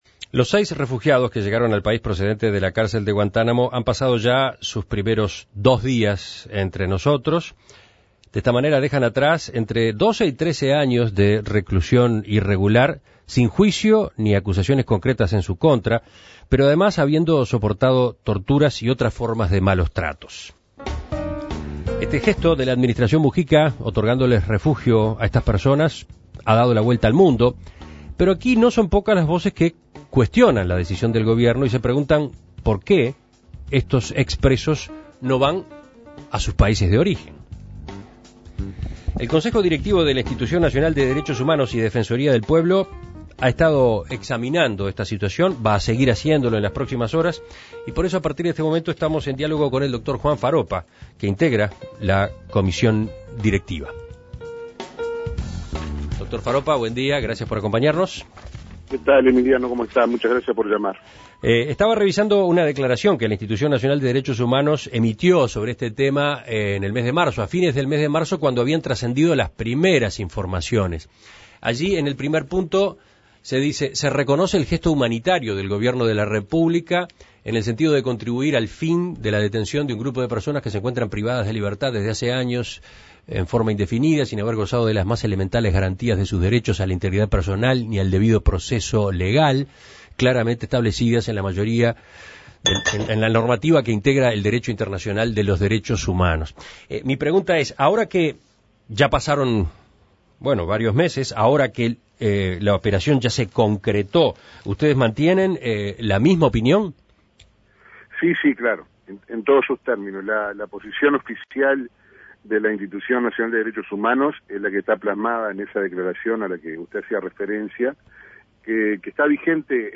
En esta oportunidad, En Perspectiva quiso conocer la visión de la Institución Nacional de Derechos Humanos (INDDHH) y Defensoría del Pueblo en esta temática, para ello se contactó con el doctor Juan Faroppa, integrante del Consejo Directivo de la institución.